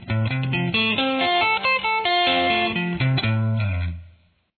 C Shape Arpeggio
This is an F major arpeggio using the C arpeggio shape.
C_style_arpeggio.mp3